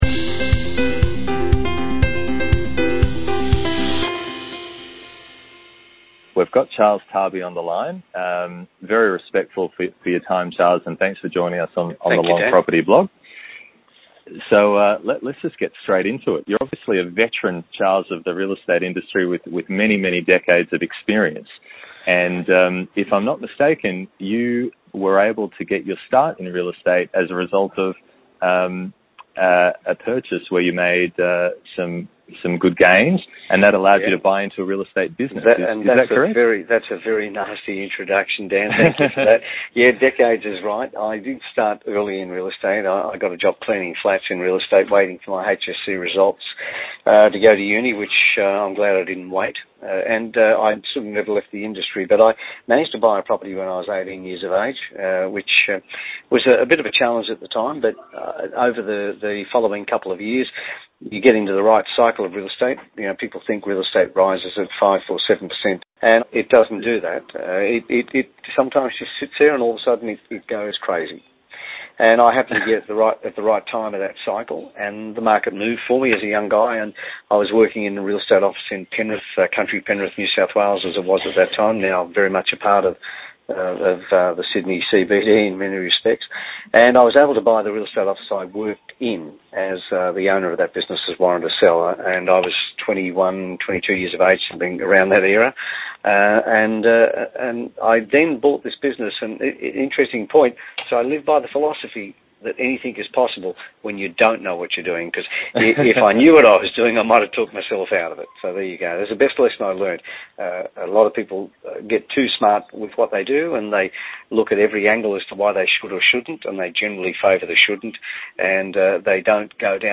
Our interview covers the following topics relevant to property investors in Australia: